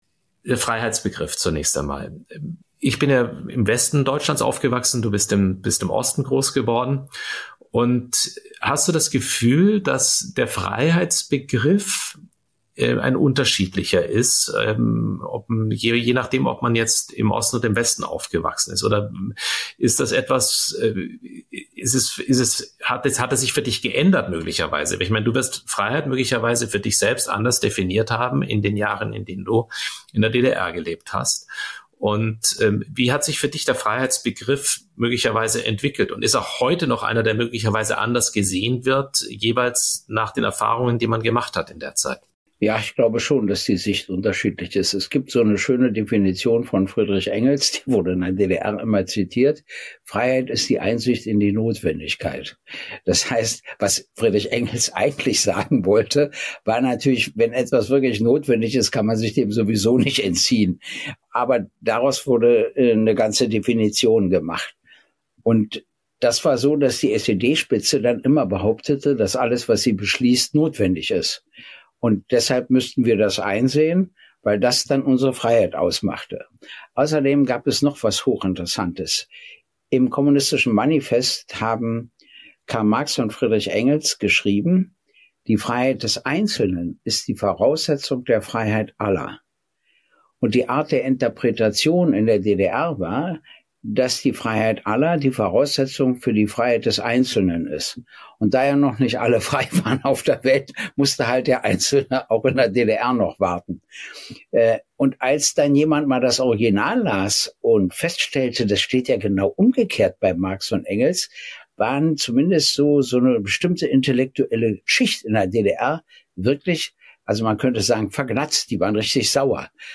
Jeden Mittwoch sitzen sie zusammen und sprechen über